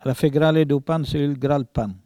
Langue Maraîchin
Catégorie Locution